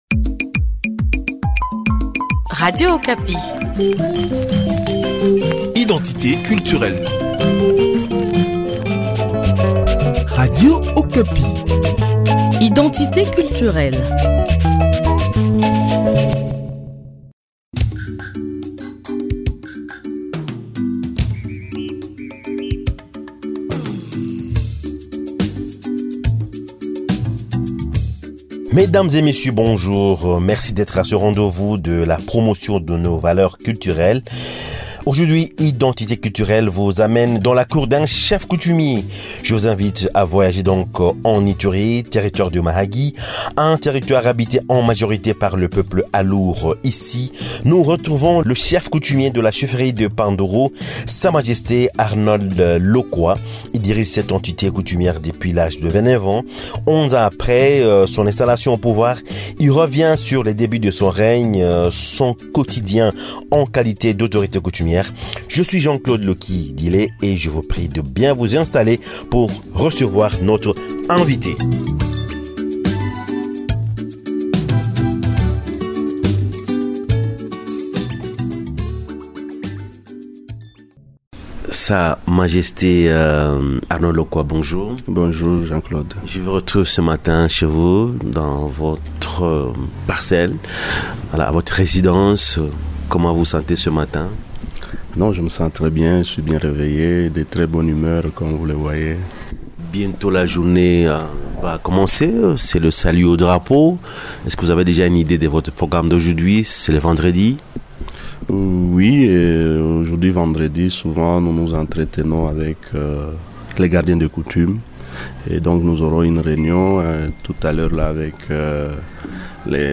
Aujourd’hui, identité culturelle vous amène dans la cour d’un chef coutumier.